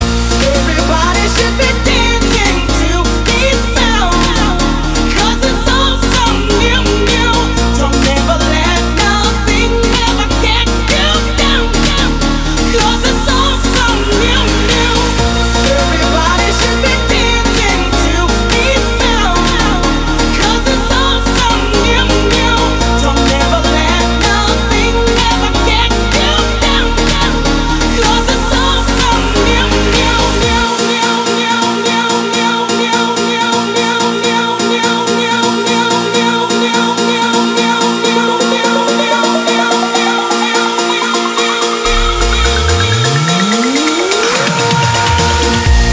• Качество: 320, Stereo
громкие
зажигательные
Electronic
EDM
клавишные
progressive house